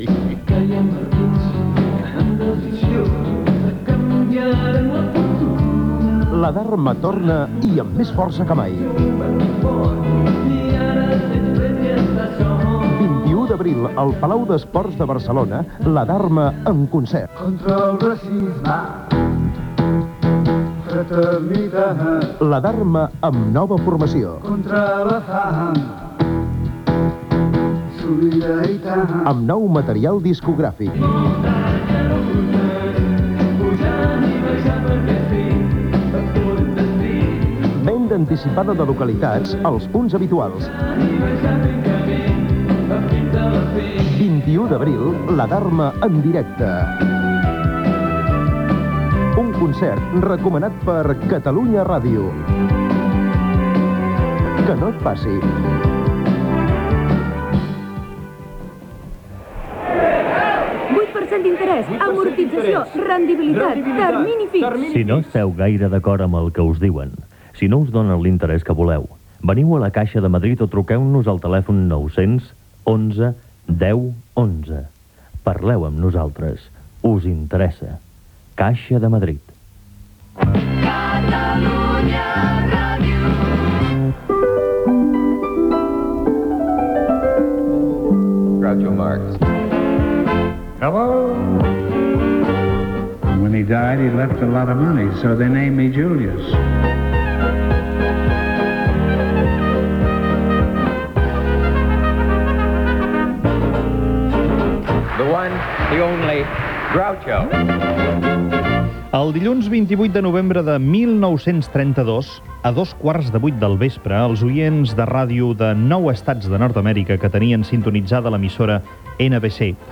Publicitat, indicatiu de l'emissora, la feina dels germans Marx a la ràdio dels Estats Units, entrevista a Màrius Serra sobre la traducció i adaptació dels guions radiofònics dels germans Marx al català
Info-entreteniment